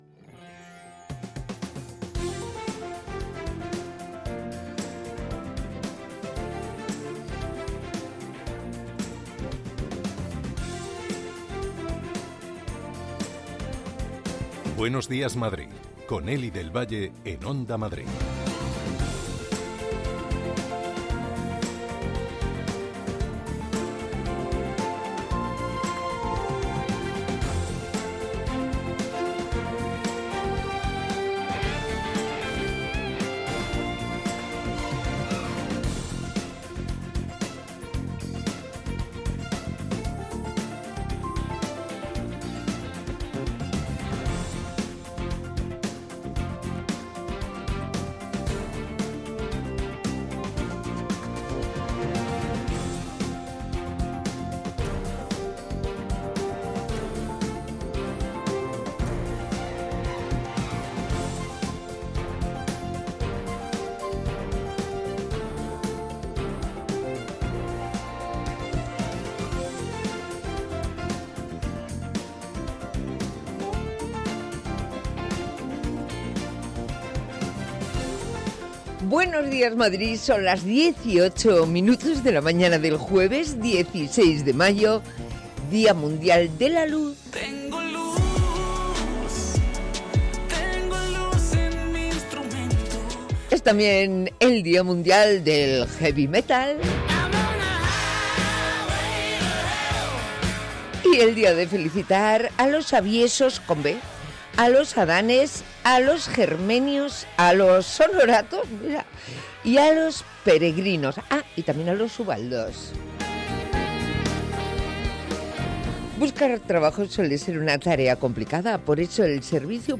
Actualidad, opinión, análisis, información de servicio público, conexiones en directo y entrevistas. Todo lo que necesitas para comenzar el día, desde el rigor y la pluralidad informativa.